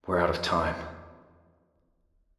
sotf_seq_goldRoom_VO_0506WeAreOutOfTimev2.wav